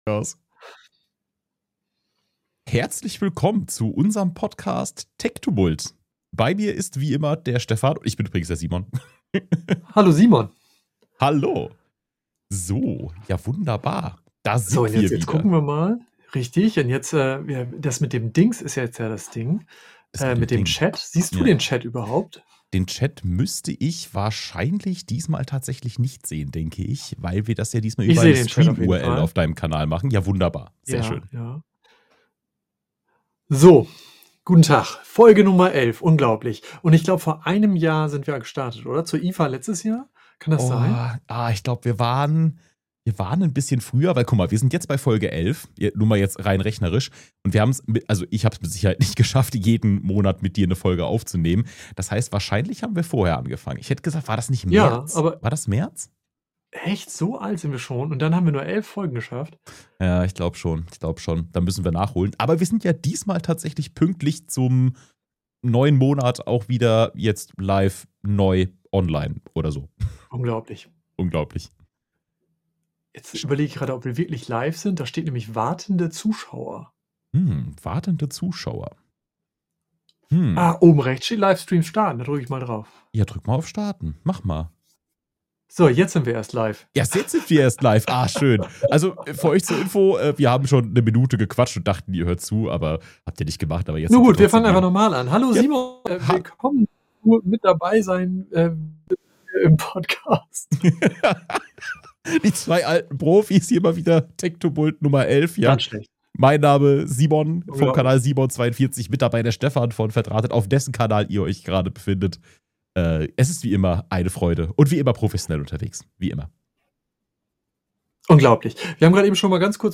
Wie immer gilt: keine Agenda, keine Vorbereitung, dafür garantiert jede Menge Chaos, spontane Themen, lustige Pannen und hoffentlich auch der ein oder andere Aha-Moment.